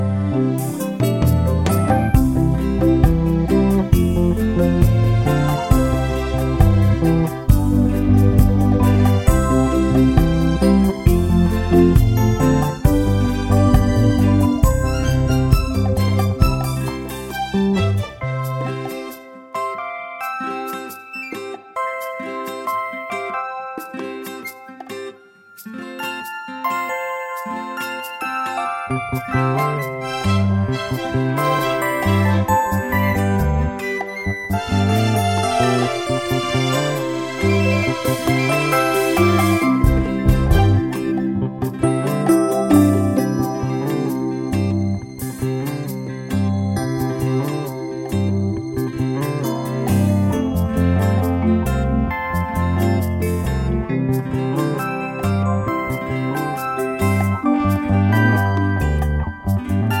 no Backing Vocals Soundtracks 2:43 Buy £1.50